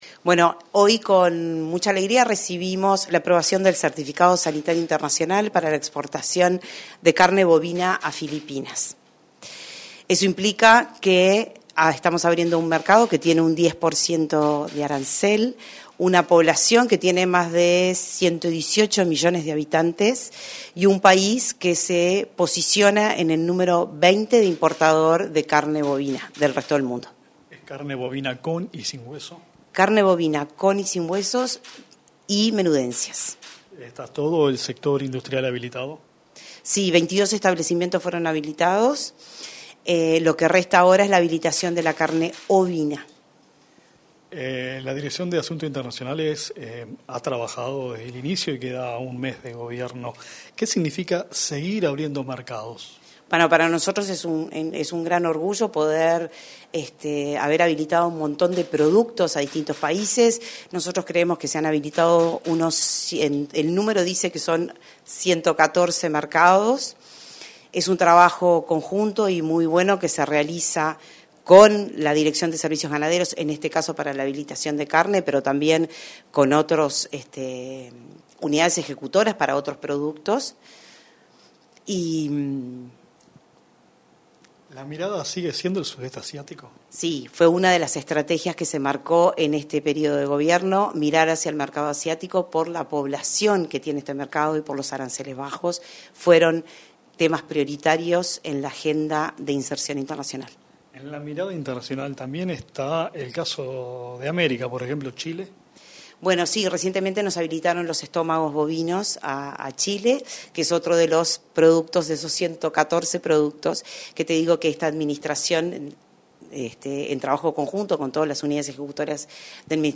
Entrevista a la directora de Asuntos Internacionales del MGAP, Adriana Lupinaci